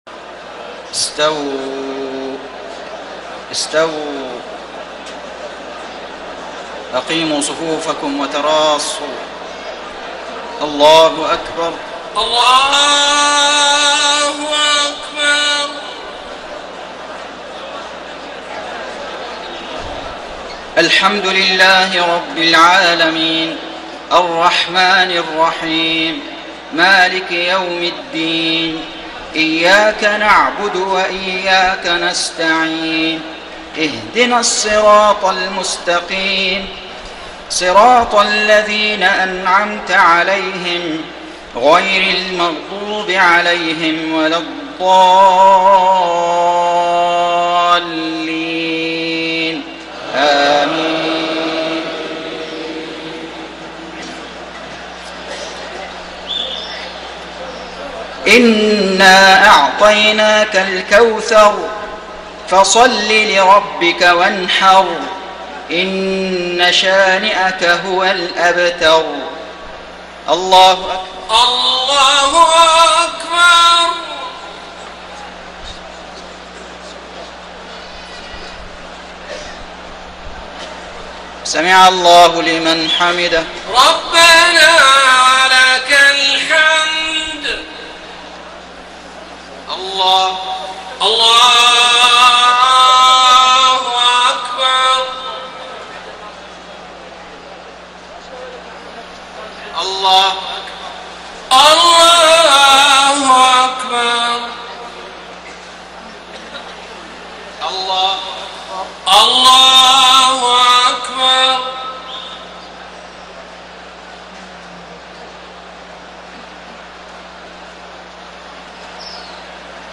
صلاة المغرب 12 ذو الحجة 1433هـ سورتي الكوثر و النصر > 1433 🕋 > الفروض - تلاوات الحرمين